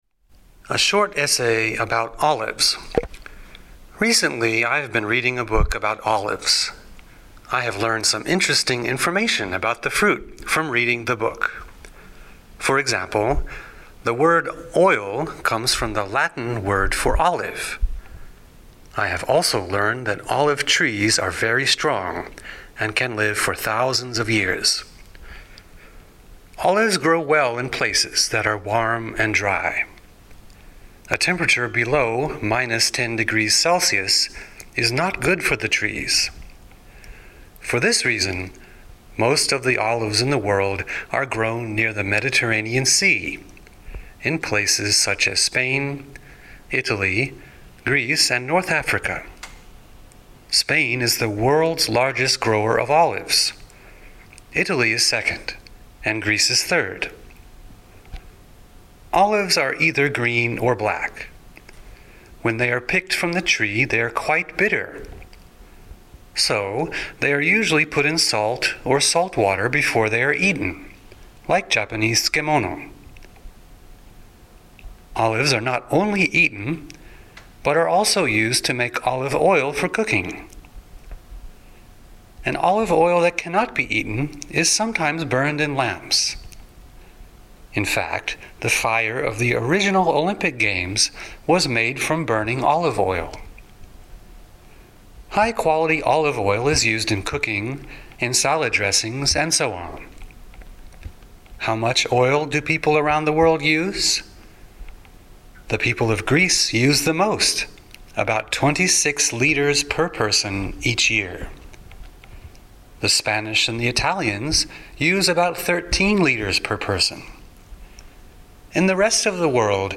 English Essay
57 A Short Essay about Olives ＲＥＡＤＩＮＧ
(slow) (fast)